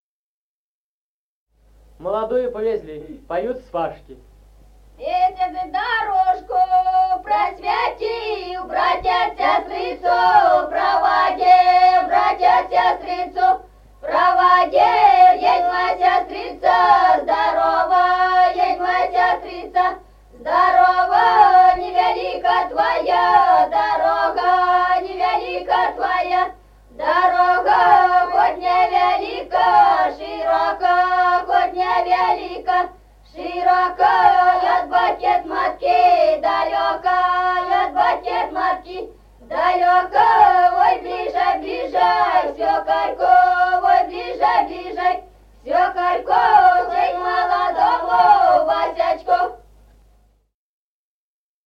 Народные песни Стародубского района «Месяц дорожку просветил», свадебная, молодую повезли, поют свашки.
(подголосник)
(запев).
с. Мишковка.